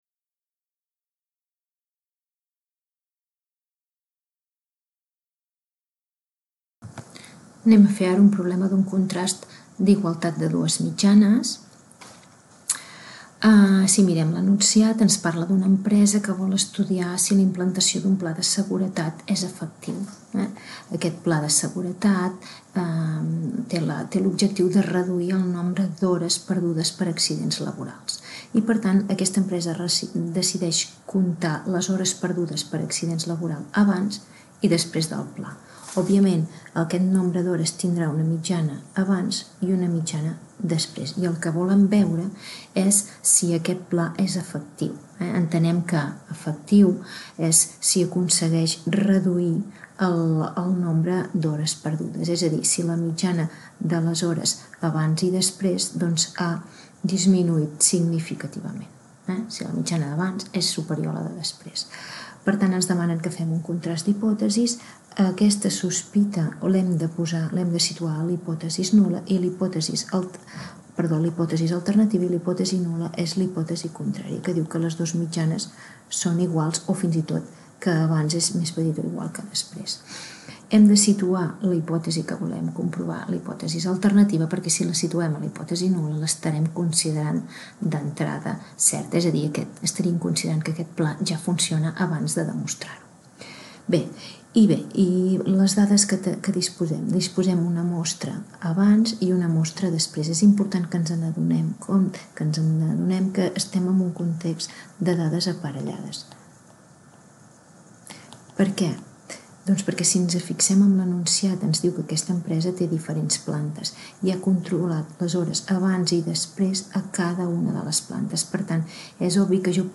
Lesson of the subject Theory of Statistics, explaining a problem about inference, the contrast of equality of means with paired data